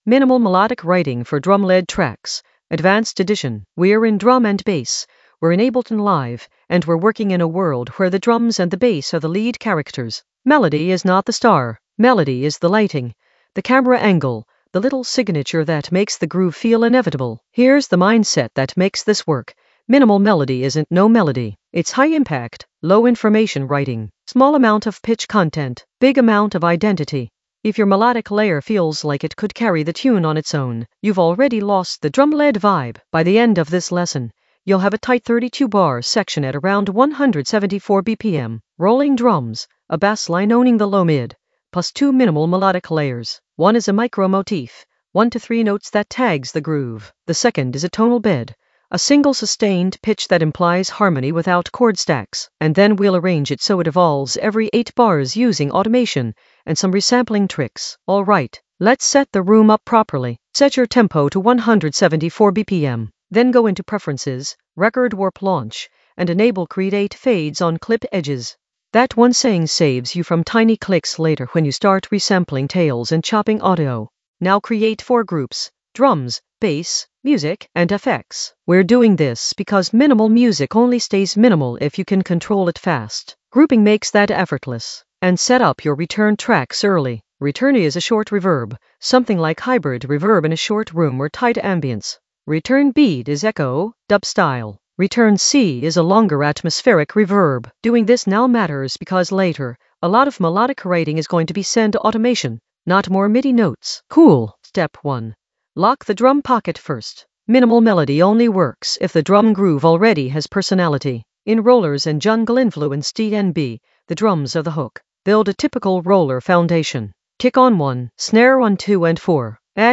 Narrated lesson audio
The voice track includes the tutorial plus extra teacher commentary.
An AI-generated advanced Ableton lesson focused on Minimal melodic writing for drum led tracks in the Composition area of drum and bass production.